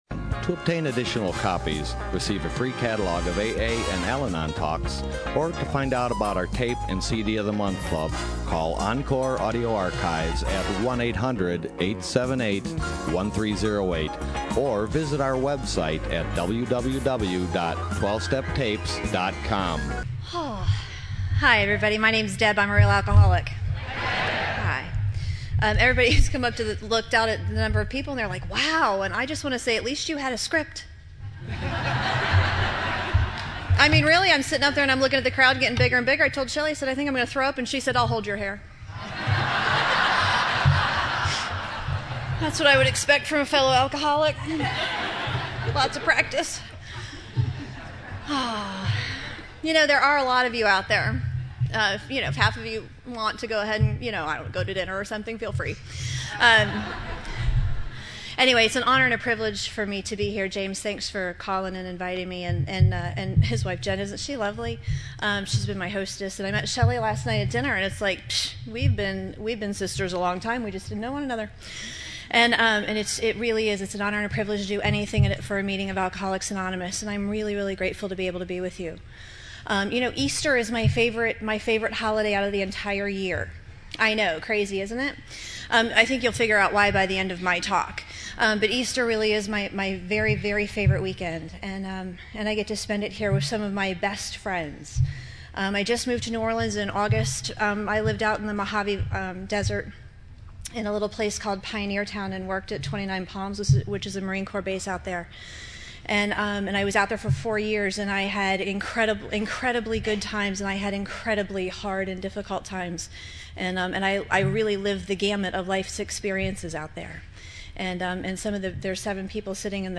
San Diego Spring Roundup 2012